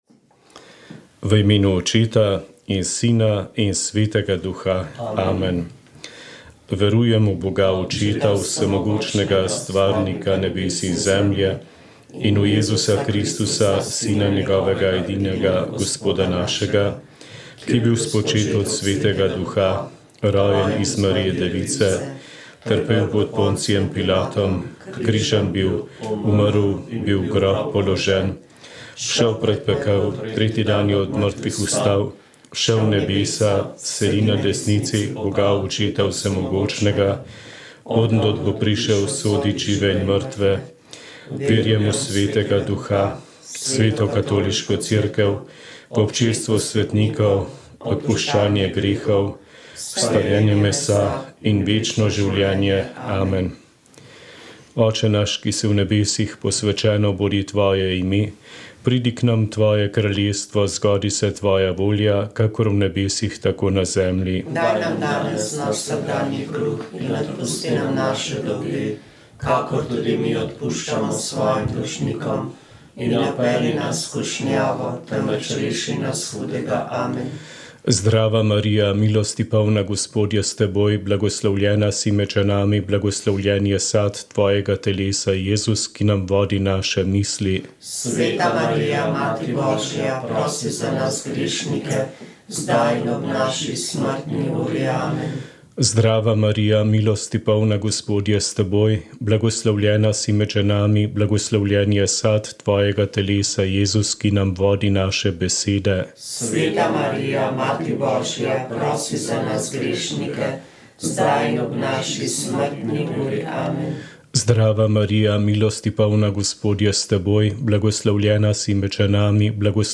Veliko več kot le pločevinasto konstrukcijo so pred 130 leti prinesli in sestavili na vrhu Triglava. Postavitvi Aljaževega stolpa in njegovemu pomenu za slovenski narod smo se posvetili v prvem delu, v nadaljevanju pa smo se spomnili še enega izjemnega podviga, ki je slovenski alpinizem postavil ob bok svetovnim velesilam: ob 50-letnici Makaluja so spregovorili nekateri akterji tega zgodovinskega vzpona. V zaključku smo namignili še na dve aktualni spodbudi za obiskovanje vrhov v tem poletju.